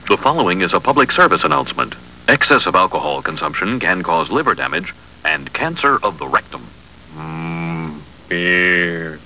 News saying "Beer is bad" to Homer